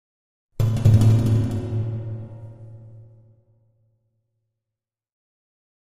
Metal Drum - Several Hits Version 2